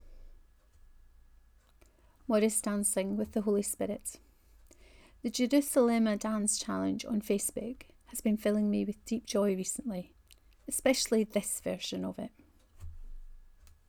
Morris dancing with the Holy Spirit 1: Reading of this post.